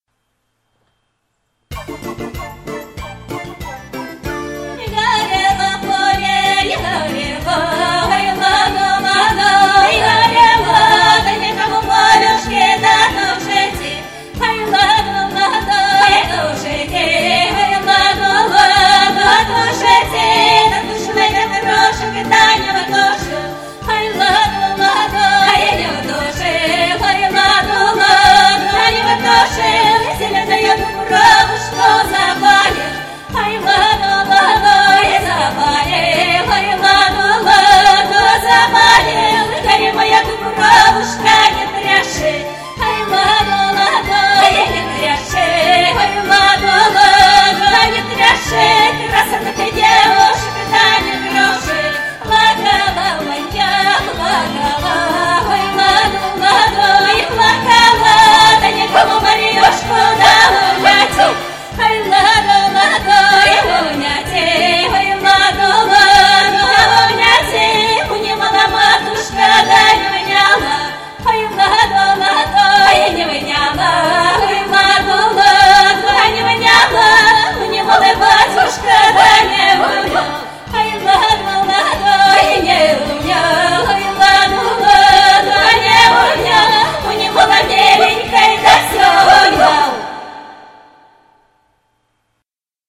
Ой ладо исполнена в самых лучших традициях фольклорного творчества, здесь и характер и техника исполнения на очень высоком профессиональном уровне, манера соответствует жанру.
Если бы профессионально записать, цены бы не было.